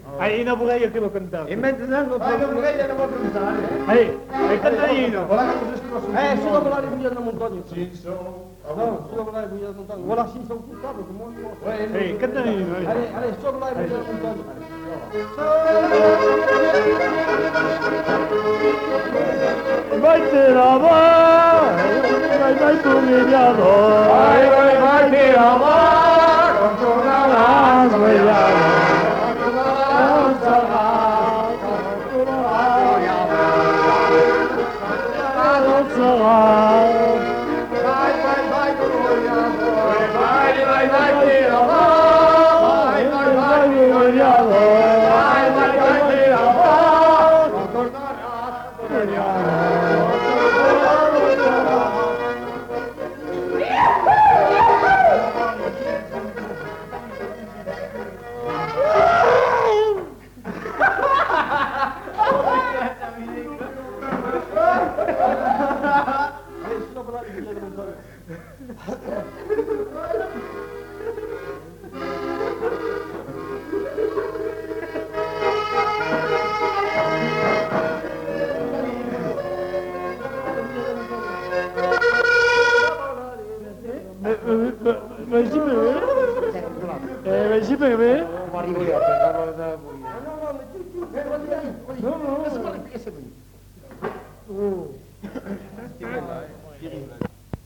Lieu : Saint-Amans-des-Cots
Genre : chanson-musique
Type de voix : voix d'homme
Production du son : chanté
Instrument de musique : accordéon chromatique
Danse : bourrée